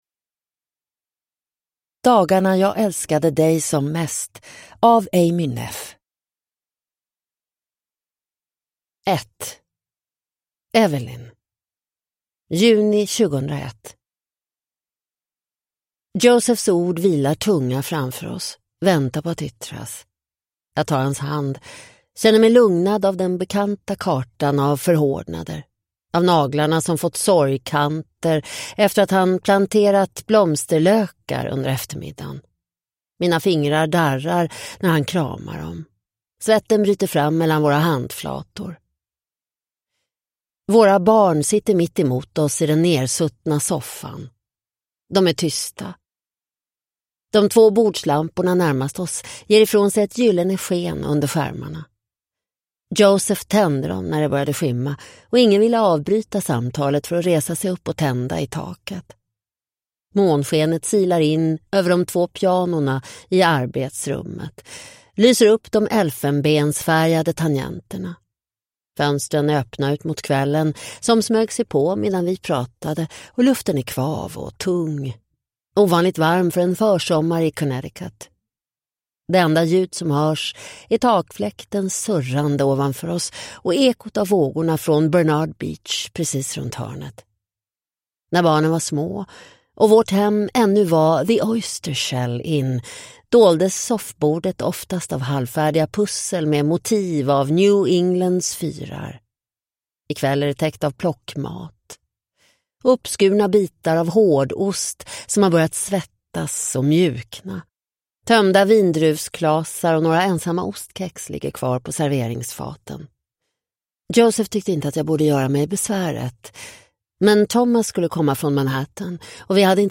Dagarna jag älskade dig som mest – Ljudbok